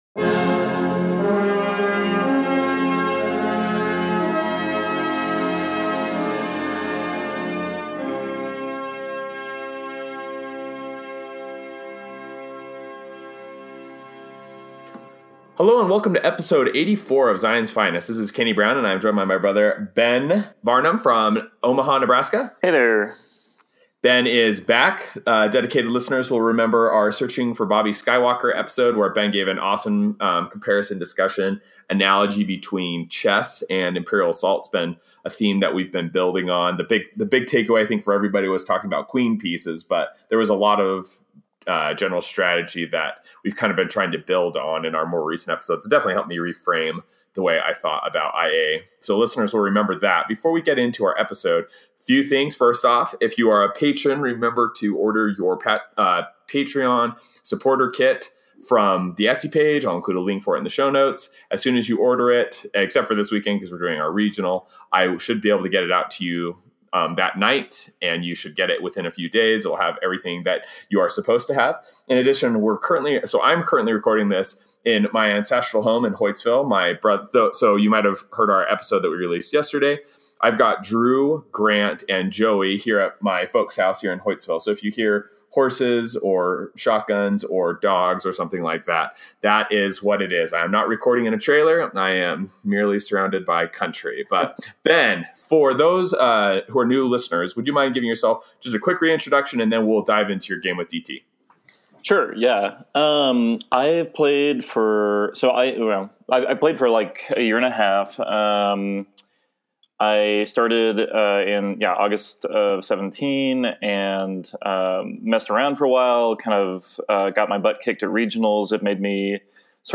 ← All episodes Ep 084 - The Boss At the Top of the Swiss Mountain 2019-01-09 Mercenary IG-88 tournament-report interview